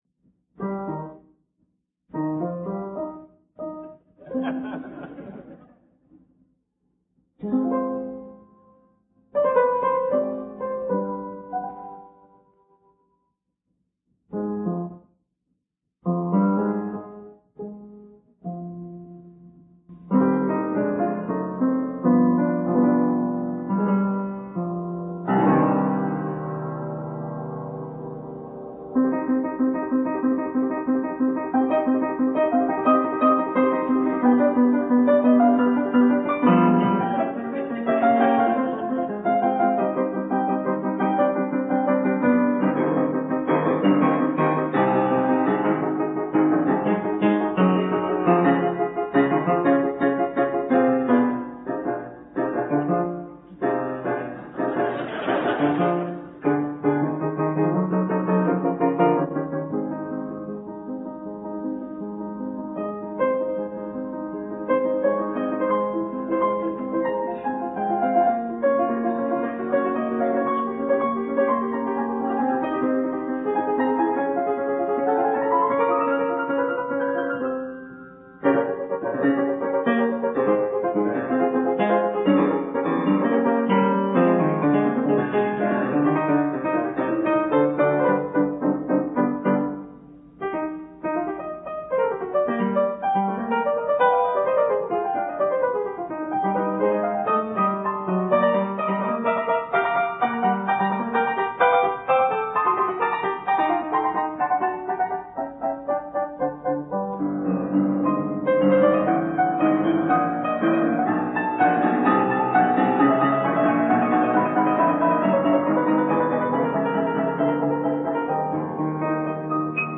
Послушаем фрагмент из этой пародийной сонаты. За фортепьяно